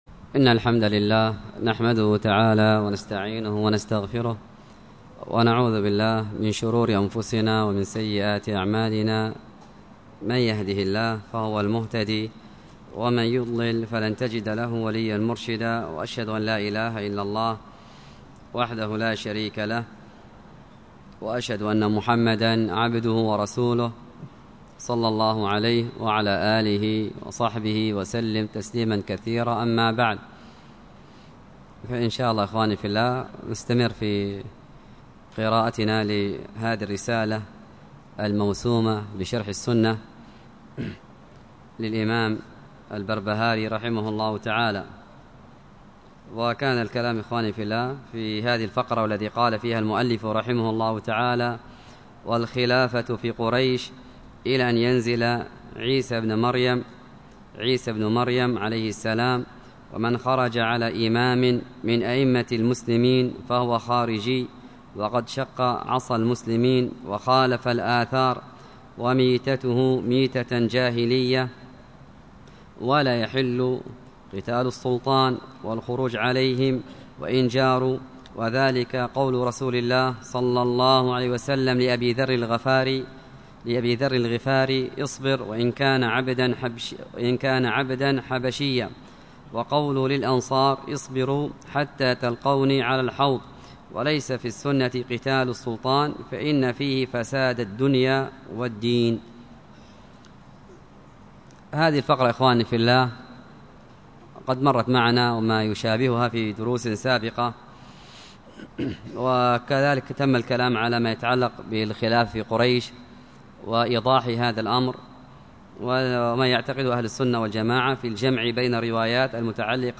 الدرس في شرح اللآلئ البهية 26، الدرس السادس والعشرين : فيه : ( وأخرج ابن جرير وابن مردوية ...